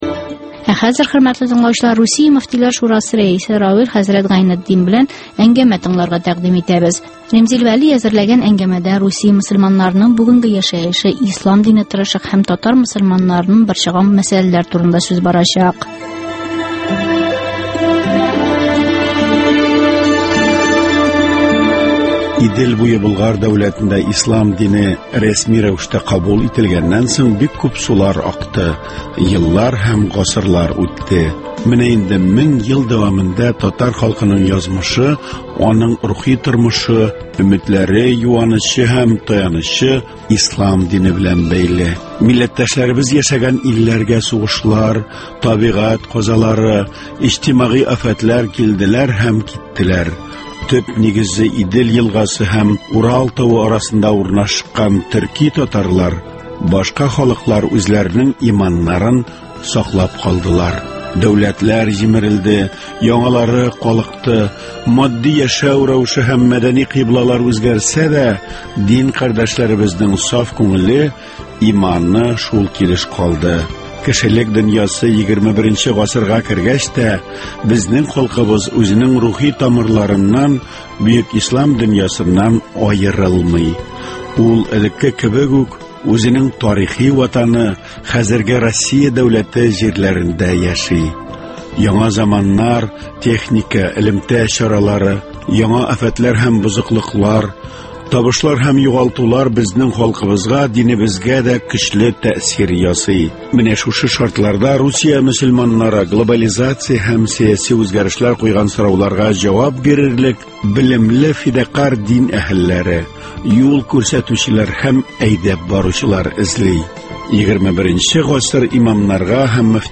Русия мөфтиләр шурасы рәисе Равил хәзрәт Гайнетдин белән әңгәмә